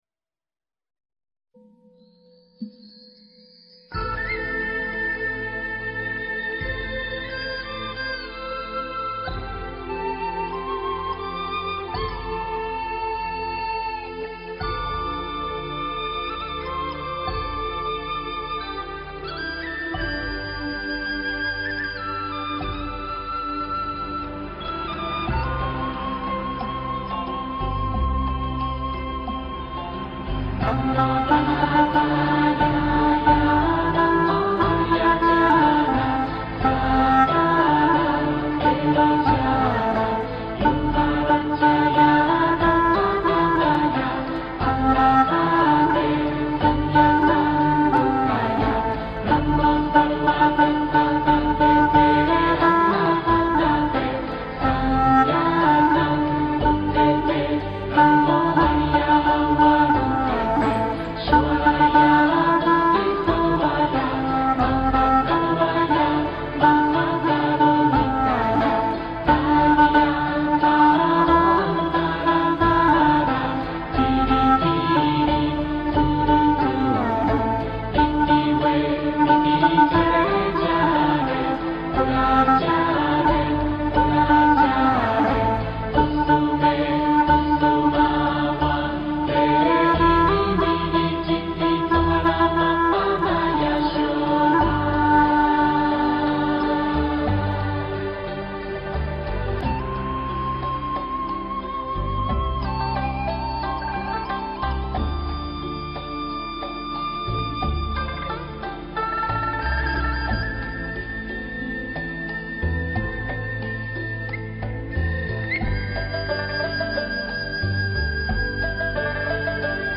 调式 : C